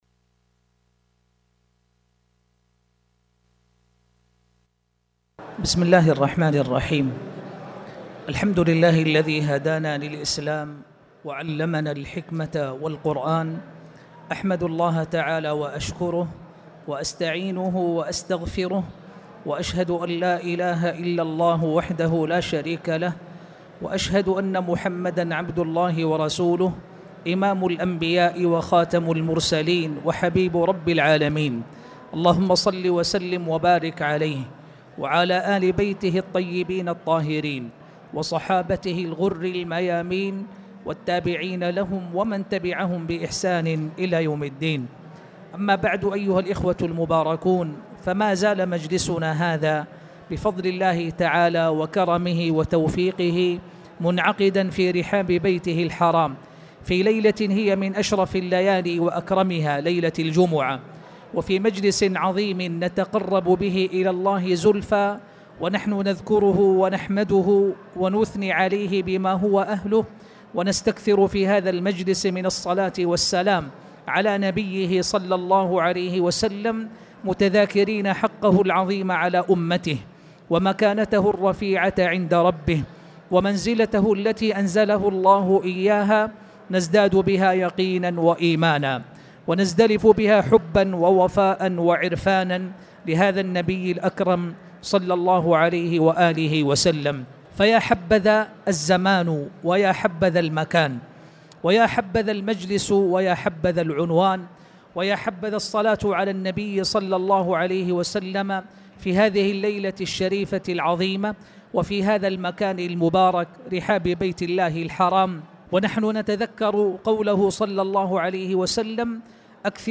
تاريخ النشر ٦ صفر ١٤٣٩ هـ المكان: المسجد الحرام الشيخ